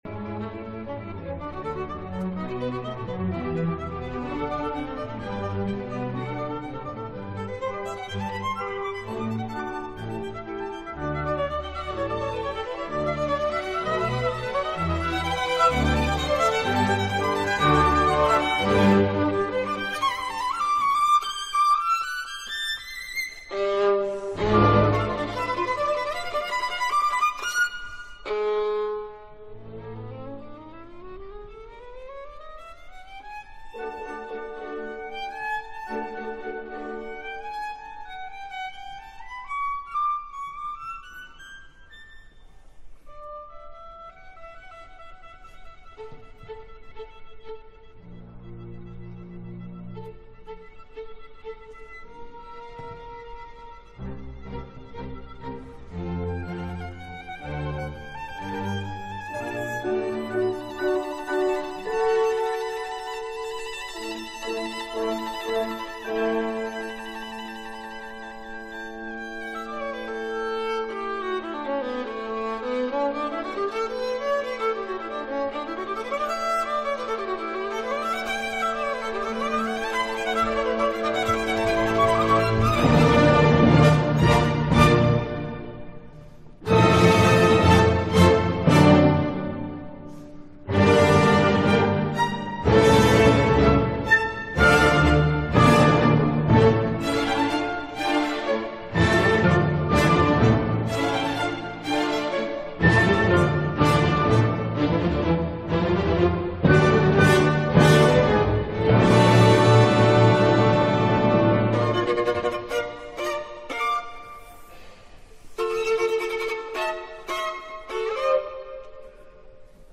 Jo no sé si la versió de Kopatchinskaja us agradarà, ara bé, garanteixo que indiferents no us deixarà, a part de tocar unes cadències diferents a les habituala, la seva versió és molt personal, ja sigui per les sonoritats a vegades més properes a una viola que a un violí, com per les “llicències” que es permet i que sobten en un concert tantes vegades escoltat i on potser ens creiem que ja ho havíem escoltat tot, doncs no, faltava com a mínim la seva versió.
Com és habitual al final de l’apunt trobareu el vídeo del concert, però per tal de que us feu una idea de l’originalitat i creativitat del tàndem Kopatchinskaja/Herreweghe us vull deixar la sorprenent cadència del final del primer moviment.
Patricia Kopatchinskaja, violin
Director Philippe Herreweghe
Alte Oper Frankfurt 31 d’octubre de 2014